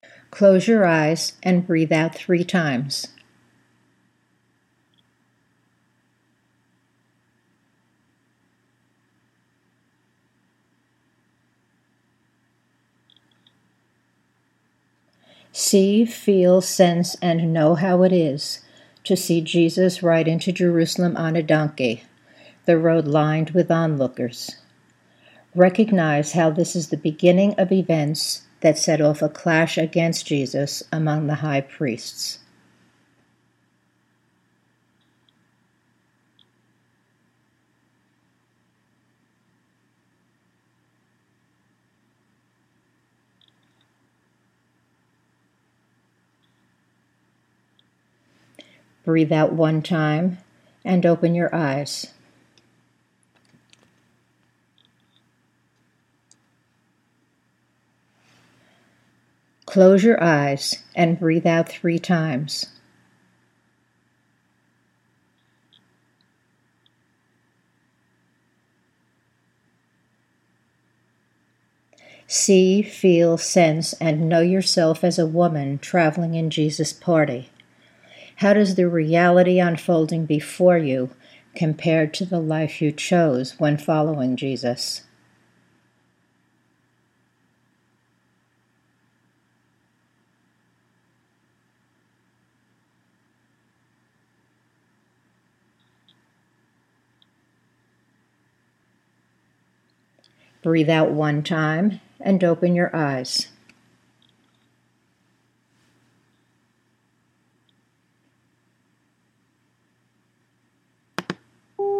Note:  Where there are two exercises to be done together, there is a space of about ten seconds between them on the tape.